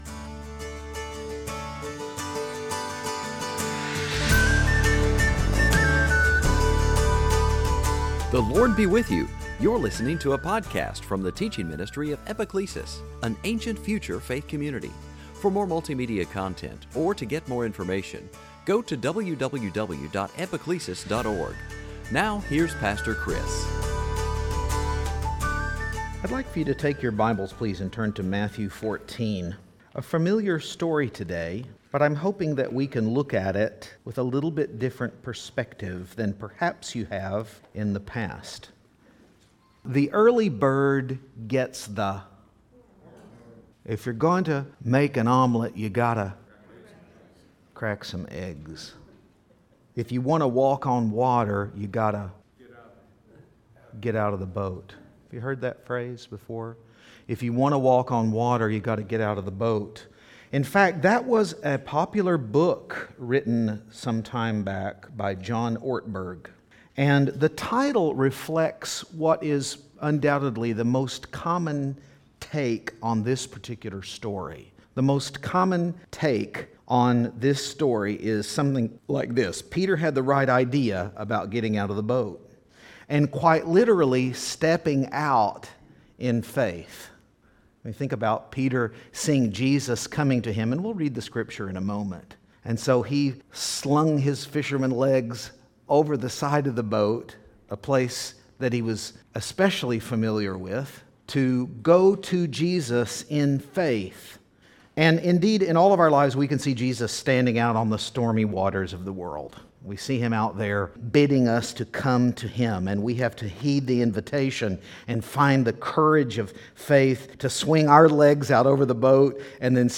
Preacher
Sunday Teaching